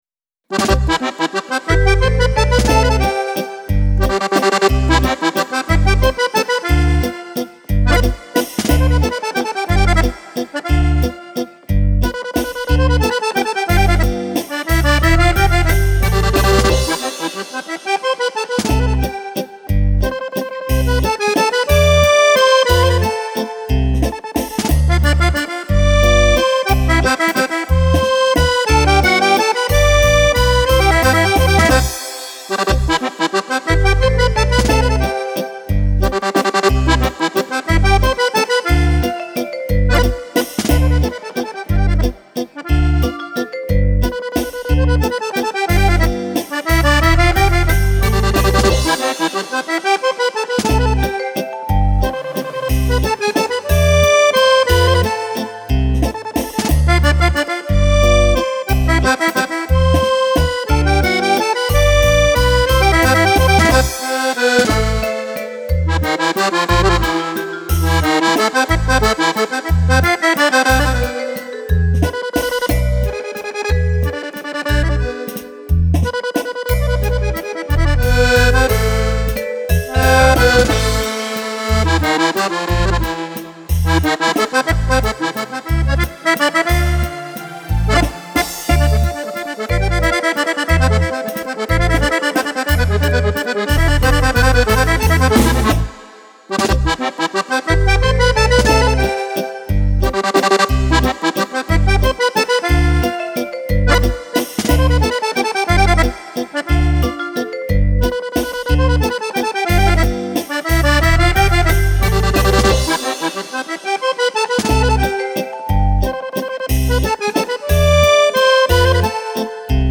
Valzer per Fisarmonica